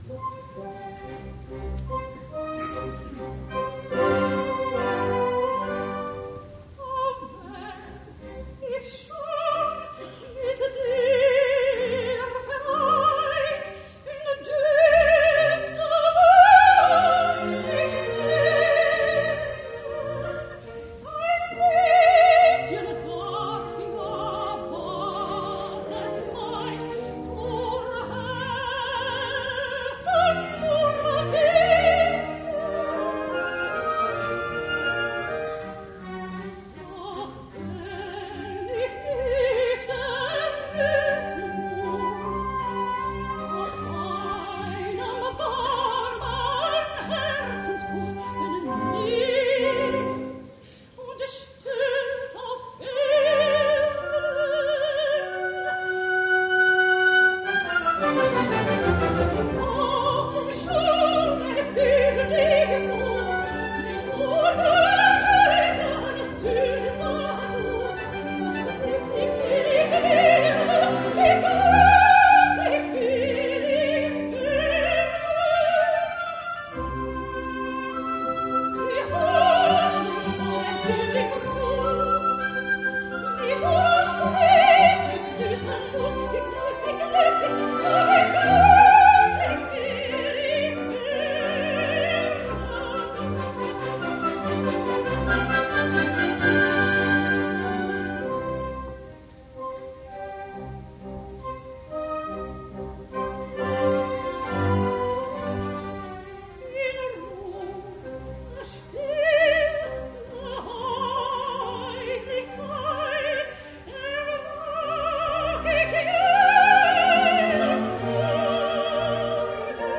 音乐 刚毅豪迈，气魄雄伟，具有哲理性、戏剧性。